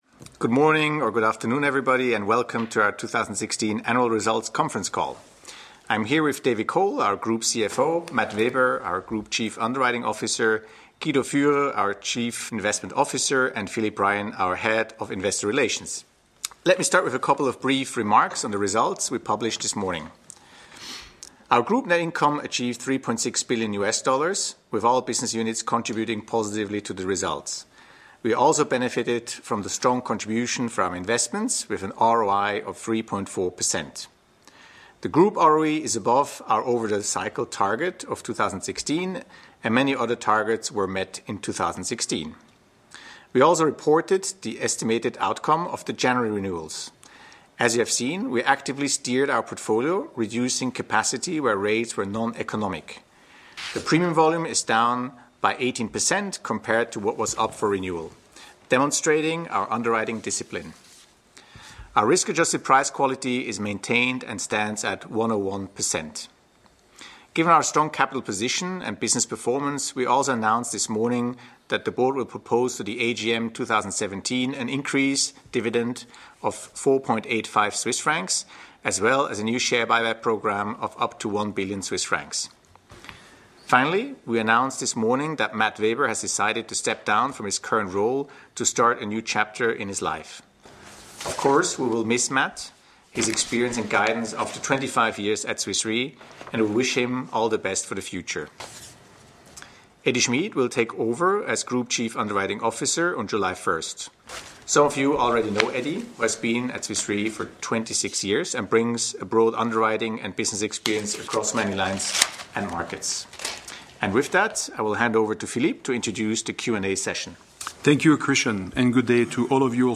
Analysts Conference call recording
2016_fy_qa_audio.mp3